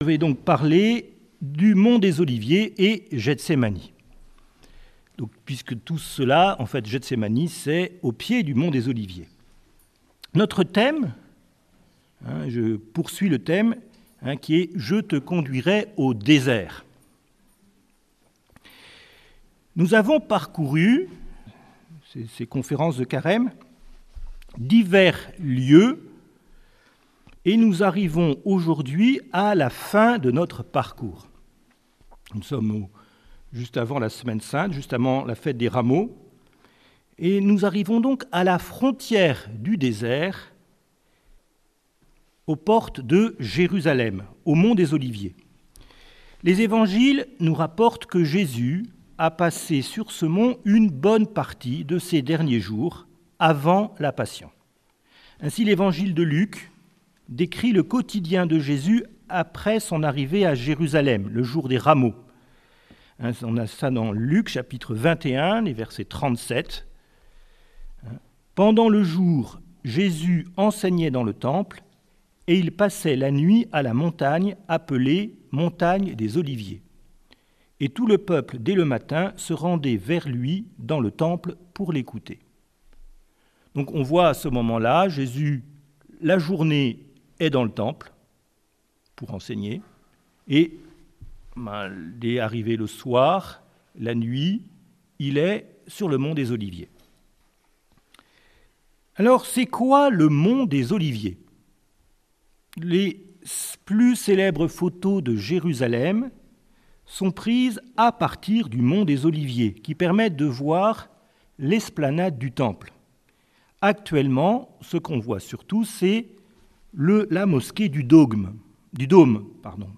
Conférence de carême des Dominicains de Toulouse du 13 avr.